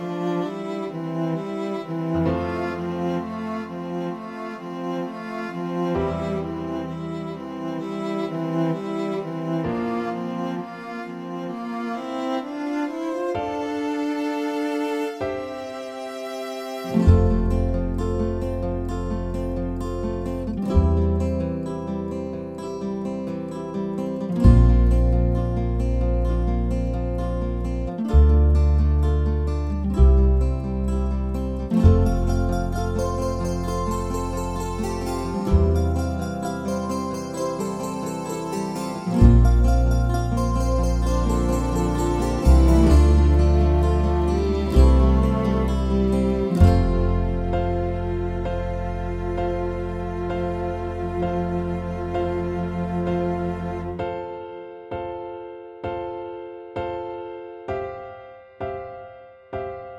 no Backing Vocals Musicals 4:30 Buy £1.50